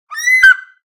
bird_eagle.ogg